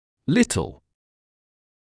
[‘lɪtl][‘литл]маленький; небольшой; мало; немного